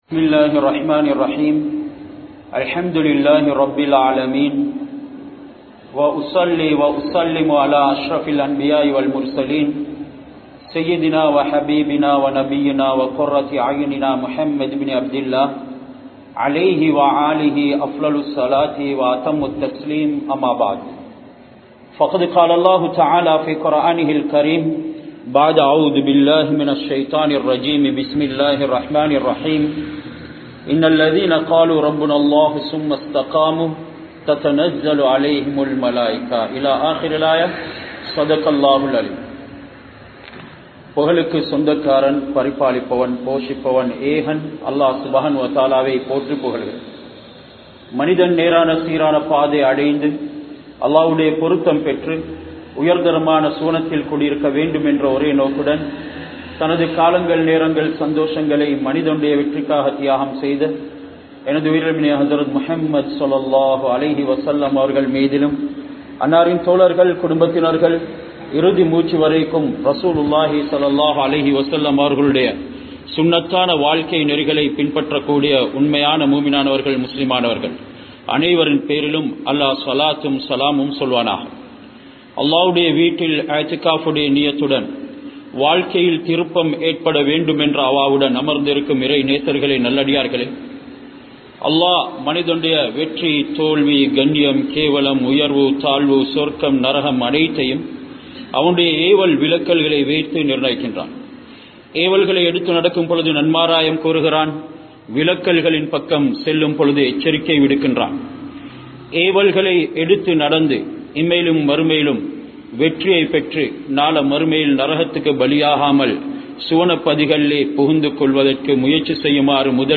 Isthiqamath (இஸ்திகாமத்) | Audio Bayans | All Ceylon Muslim Youth Community | Addalaichenai
Kandy, Galagedara, Anwar Jumua Masjidh